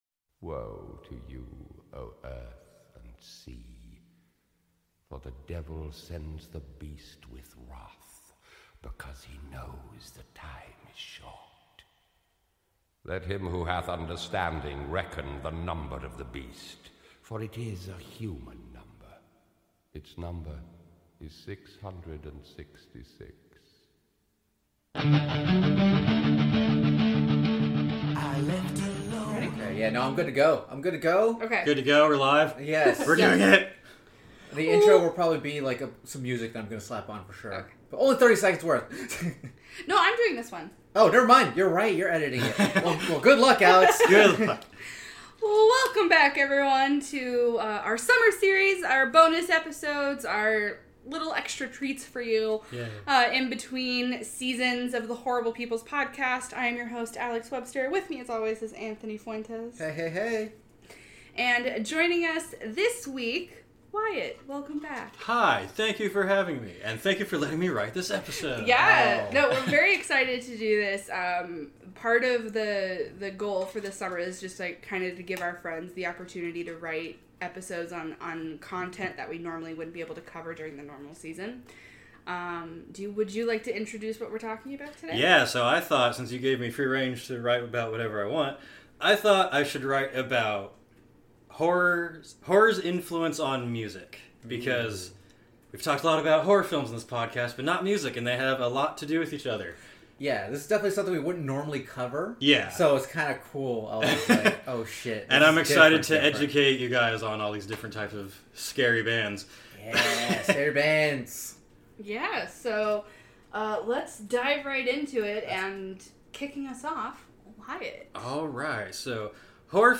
Lots of music clips and nostalgia in this one!